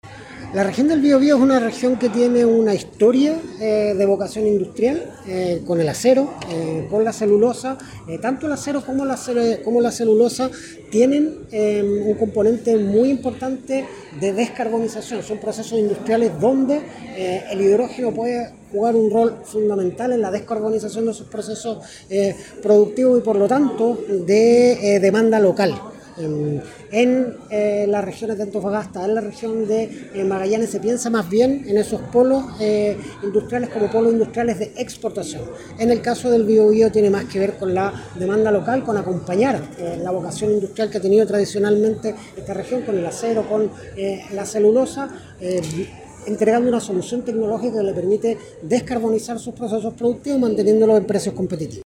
Este martes se realizó en Biobío el Green Hydrogen Summit Chile LAC 2025, instancia donde se abordaron las oportunidades y desafíos del desarrollo energético regional, por primera vez fuera de Santiago, considerando el rol estratégico de la industria local.